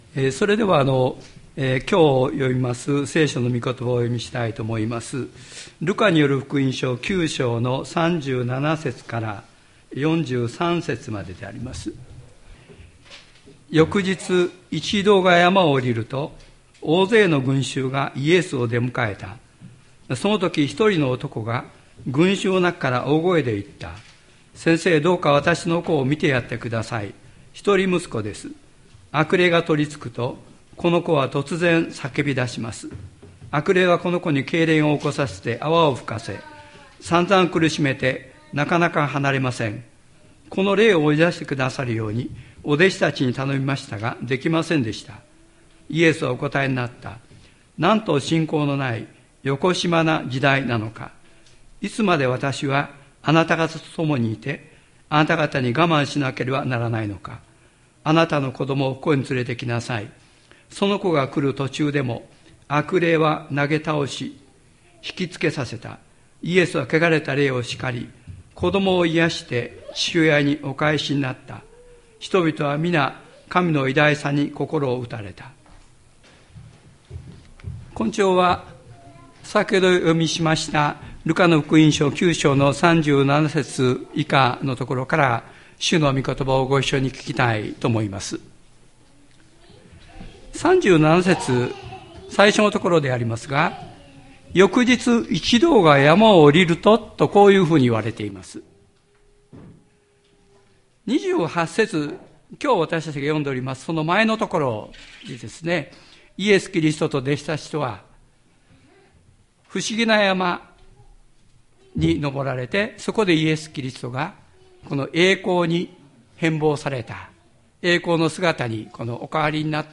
千里山教会 2023年10月29日の礼拝メッセージ。